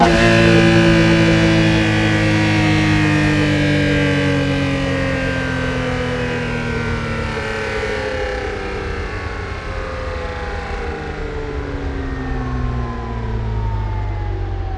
rr3-assets/files/.depot/audio/Vehicles/v12_02/v12_02_Decel.wav
v12_02_Decel.wav